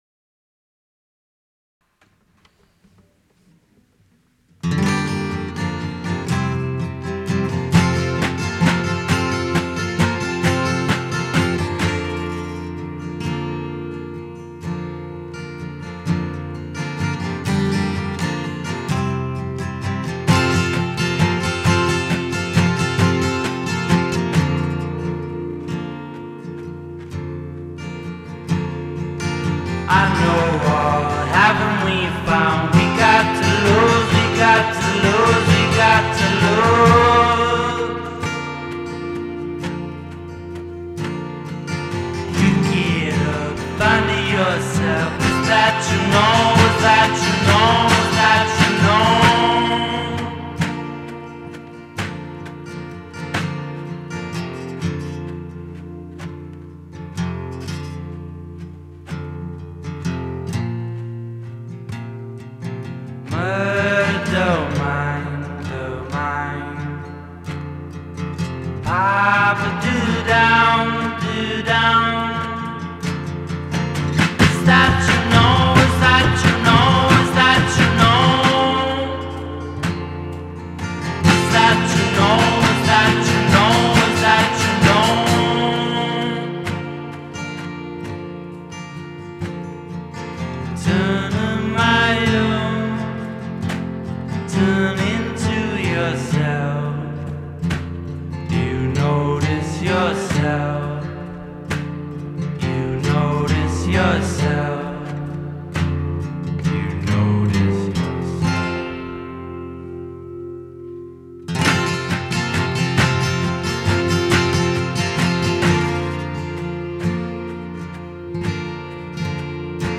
Asian influences are haunting.